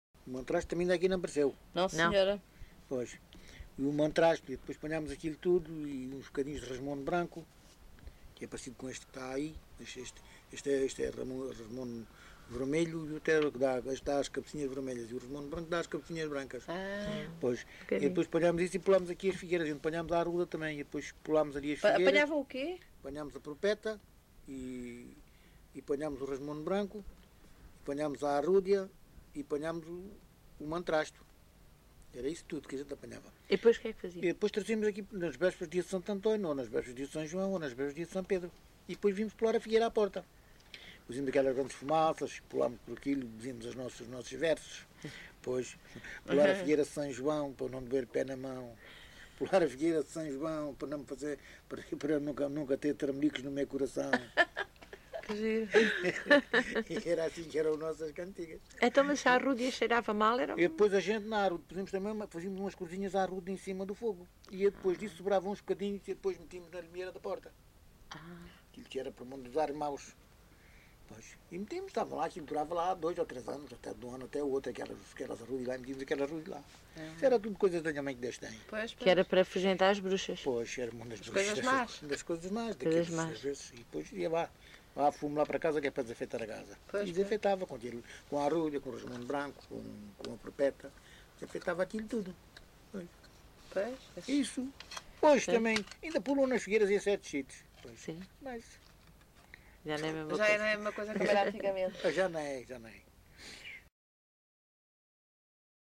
LocalidadeVale Chaim de Baixo (Odemira, Beja)